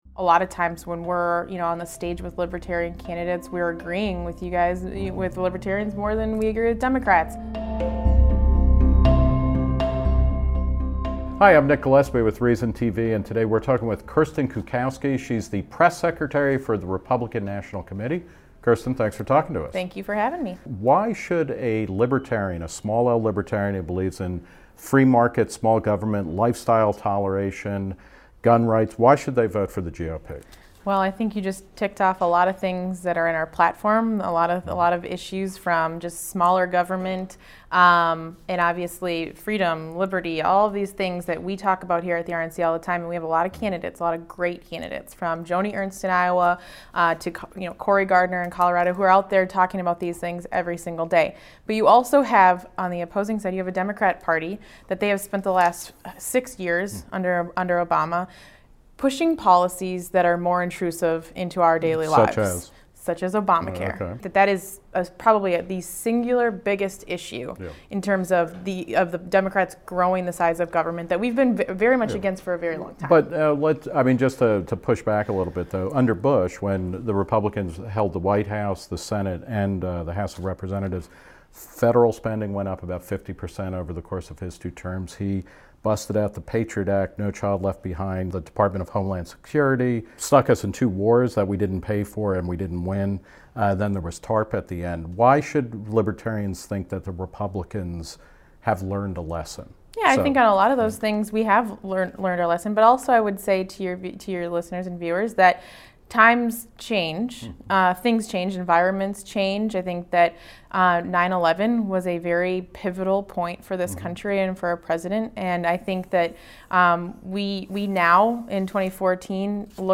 Note: We reached out the Democratic National Committee but were unable to schedule an interview.